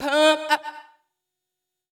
House / Voice / VOICEGRL068_HOUSE_125_A_SC2.wav